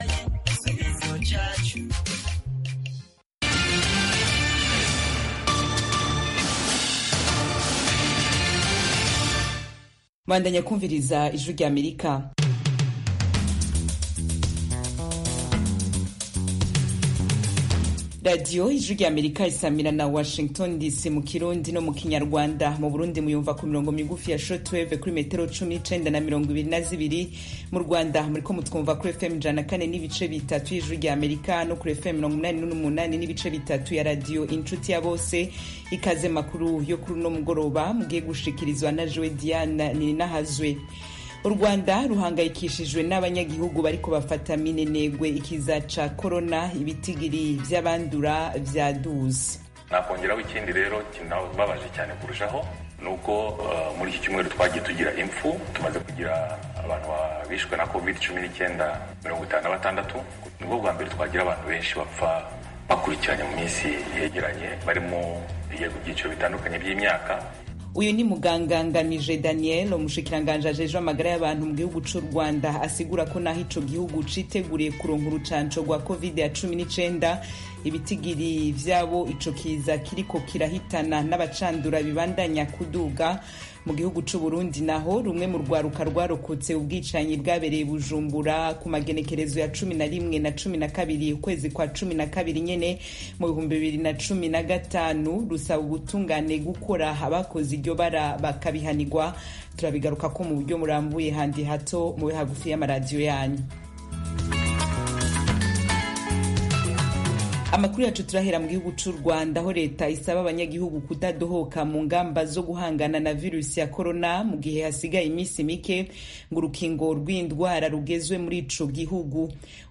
Amakuru y'Akarere (1600-1630 UTC): Amakuru atambuka i saa kumi n'ebyeri ku mugoroba mu Rwanda no mu Burundi. Akenshi, aya makuru yibanda ku karere k'ibiyaga bigari n'Afurika y'uburasirazuba.